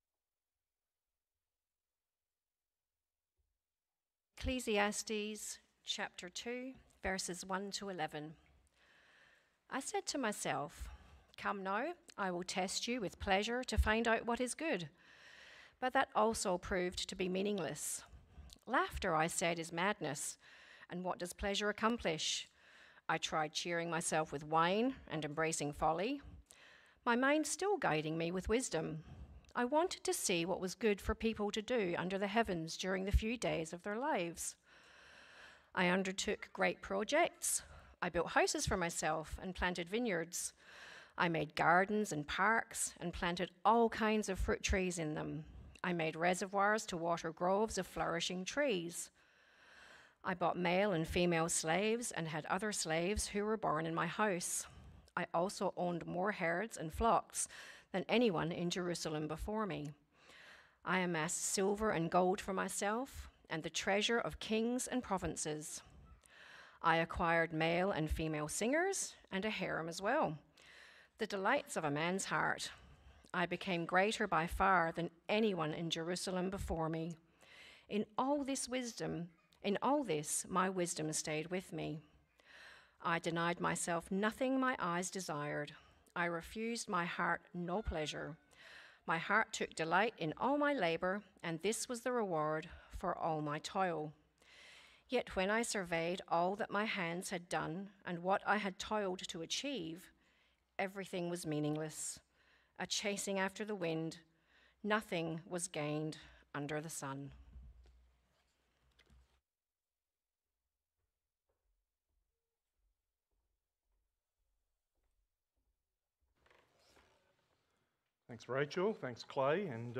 Sermons | Riverbank Christian Church